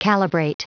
Prononciation du mot calibrate en anglais (fichier audio)
Prononciation du mot : calibrate